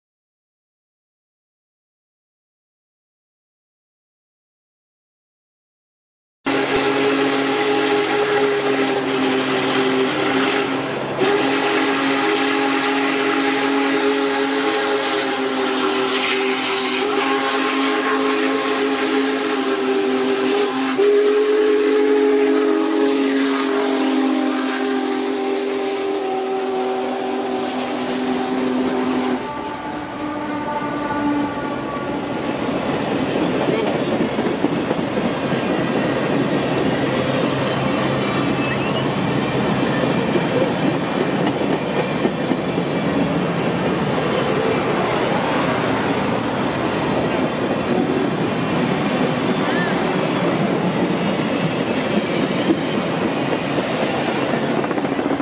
Union Pacific 4014 (Big Boy) Locomotive Pass-By, D'Hanis, Texas